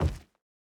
added stepping sounds
Rubber_03.wav